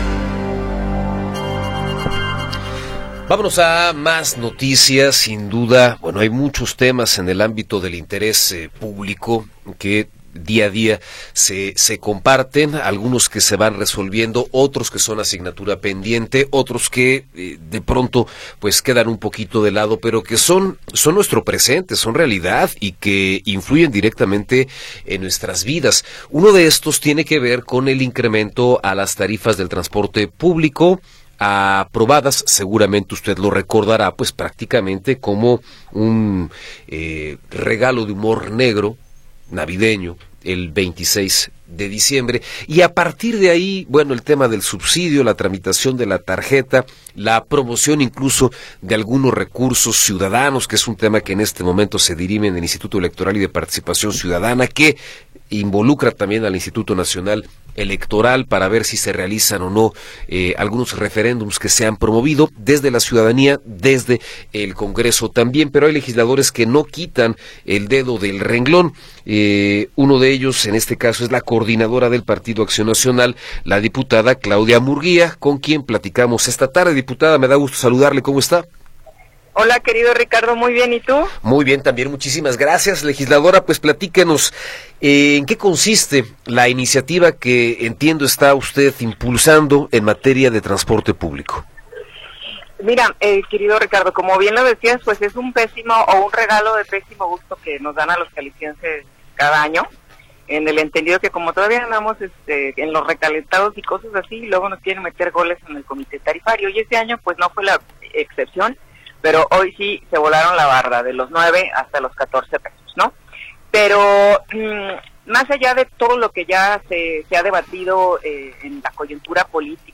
Entrevista con Claudia Murguía Torres
Claudia Murguía Torres, coordinadora de la fracción del PAN en el Congreso de Jalisco, nos habla sobre la iniciativa para no condicionar subsidios al transporte público.